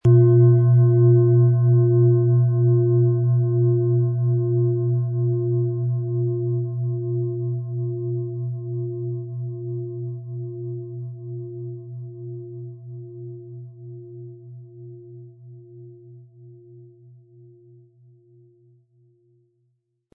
Planetenton
Von Hand getriebene Schale mit dem Planetenton Mond.Die Klangschalen lassen wir von kleinen Manufakturen anfertigen.
Aber dann würde der ungewöhnliche Ton und das einzigartige, bewegende Schwingen der traditionellen Herstellung fehlen.
Der passende Schlegel ist umsonst dabei, er lässt die Schale voll und harmonisch tönen.
MaterialBronze